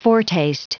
Prononciation du mot foretaste en anglais (fichier audio)
Prononciation du mot : foretaste
foretaste.wav